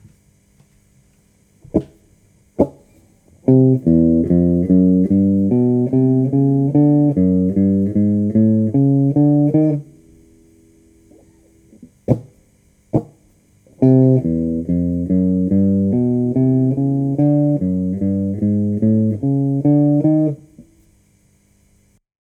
Guitare 2 : ligne de basse
Audio de la ligne de basse seule :